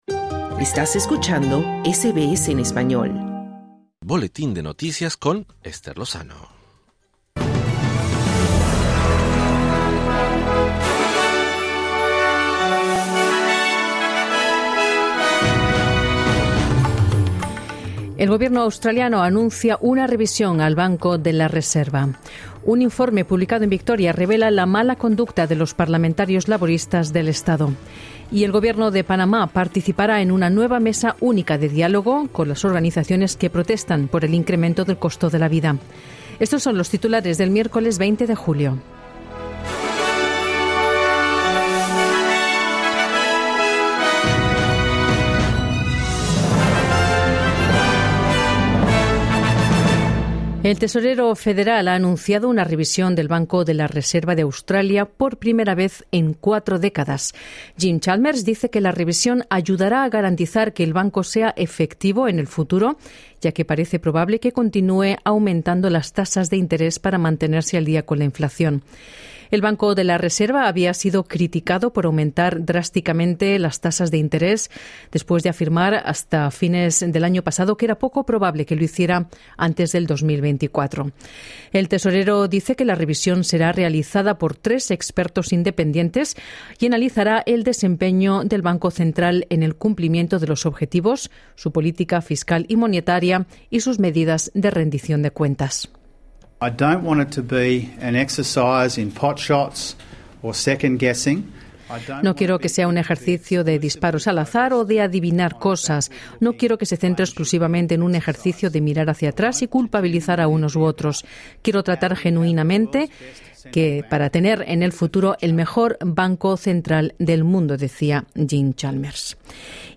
Noticias SBS Spanish | 20 julio 2022